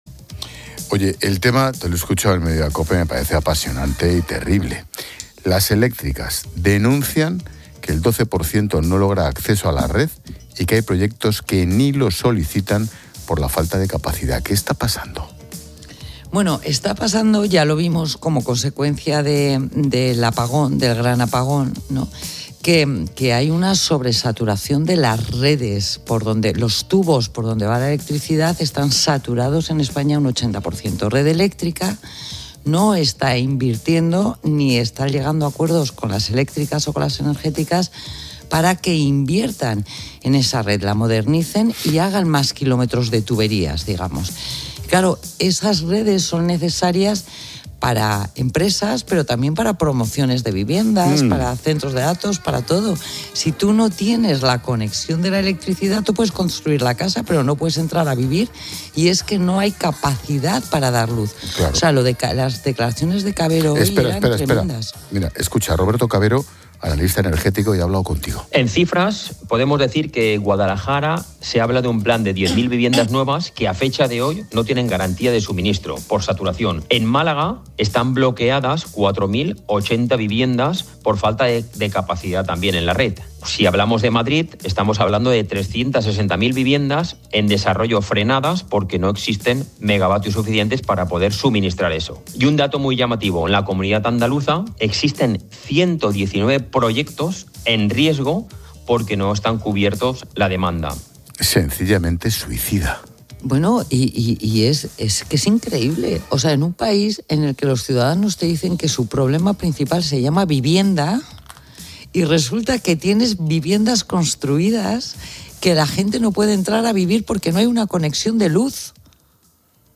Pilar García de la Granja, directora de Mediodía COPE, ha analizado en 'La Linterna' con Ángel Expósito el grave problema de la red eléctrica en España.